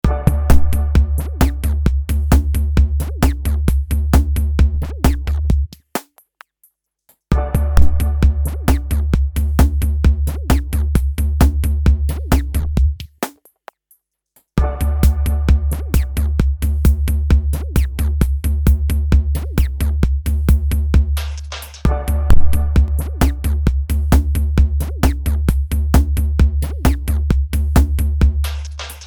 it is very interesting that other sounds don’t spoil, only sharp blows.
first time i’m tweaking FLTF for all tracks and in second pattern first snare hit is crashed.
in the next pattern i’m tweaking only snare filter and after restoring kit bug is the same.